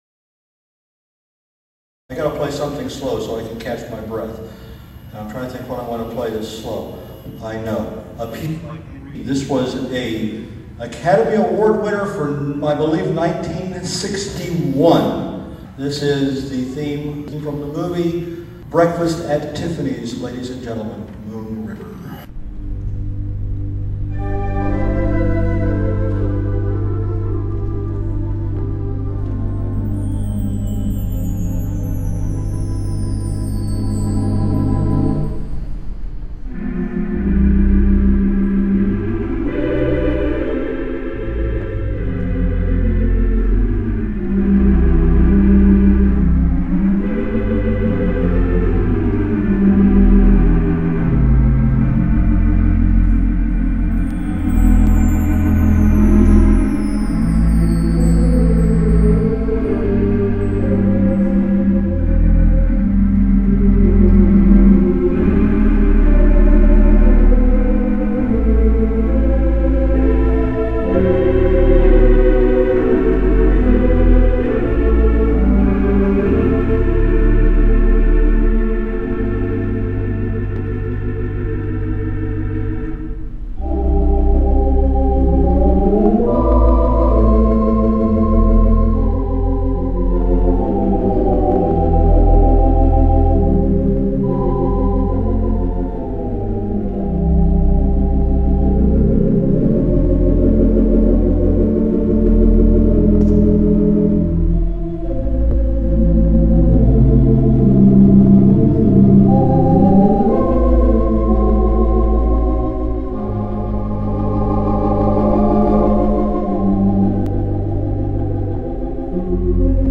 Listen to the 4/28 Mighty WurliTzer Theatre Pipe Organ installed at the Alabama Theatre in Birmingham, Alabama!
These tracks were recorded live on his Dell laptop computer using a Berhinger USB mixer and CAD condenser microphones.
There are some pops and crackles here and there that our software would not remove.